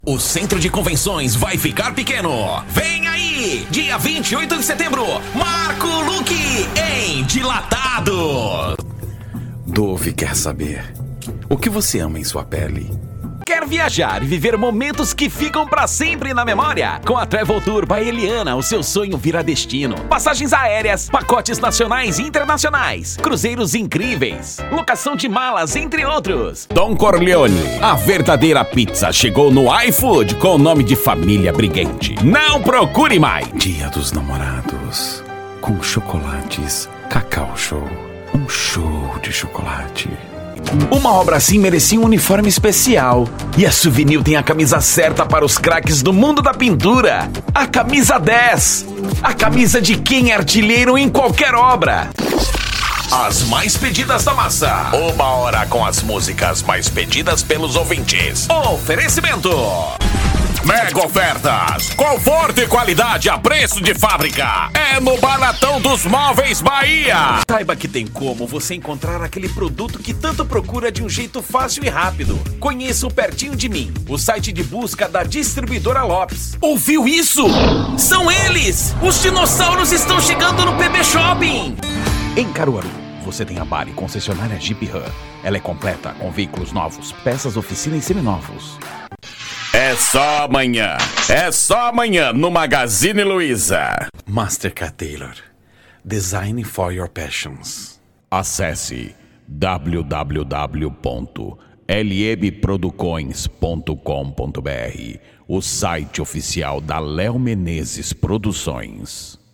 Spot Comercial
Impacto
Animada
Caricata